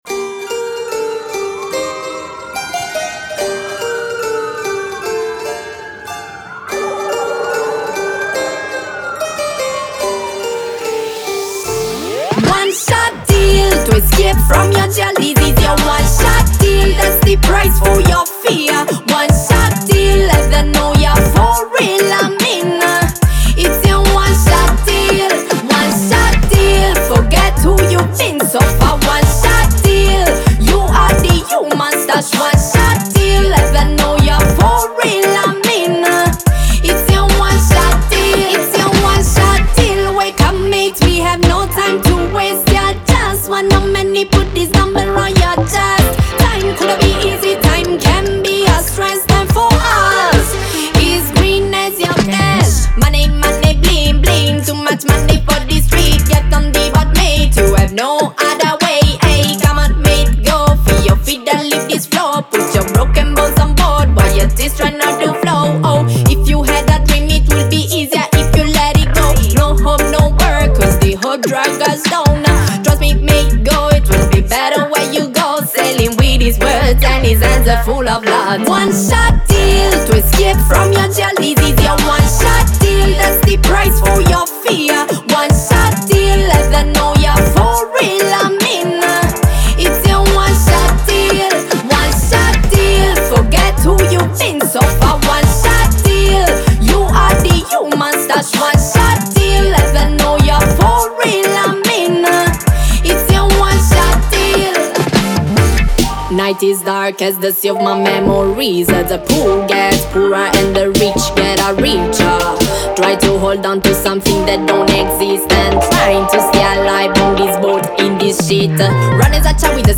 vocals
drums
guitar
bass
keyboards
Genre: Reggae